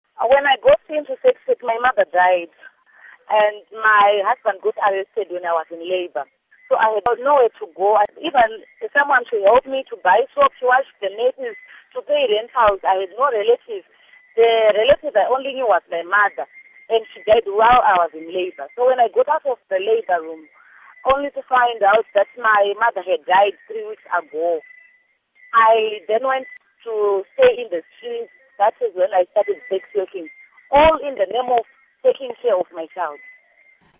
Nhau